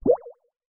Cute Notification.wav